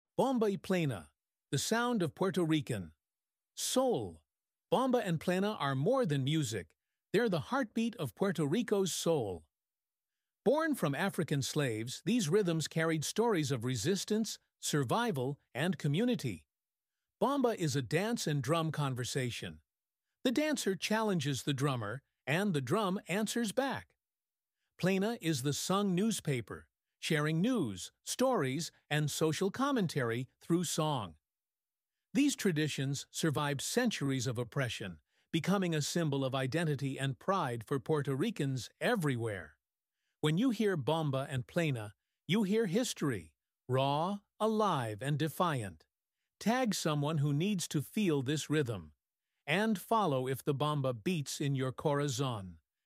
Bomba y Plena — The Sound of Puerto Rican Soul